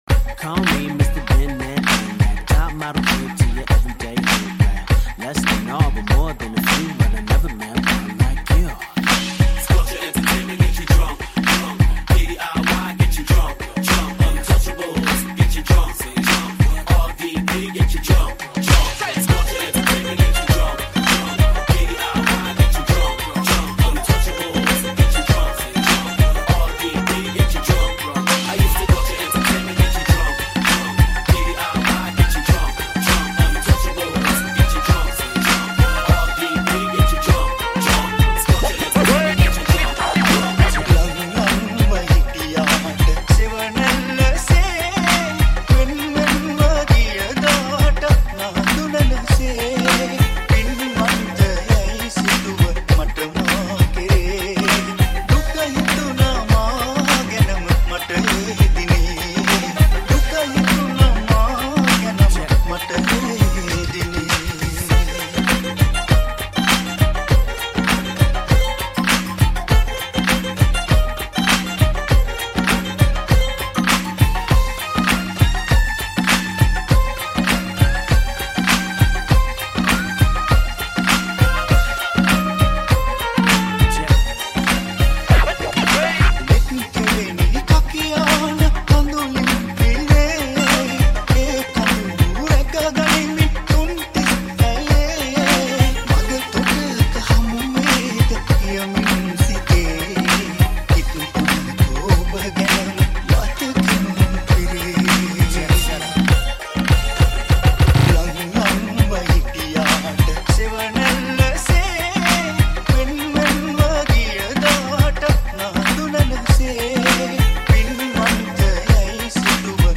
Sinhala Remix New Song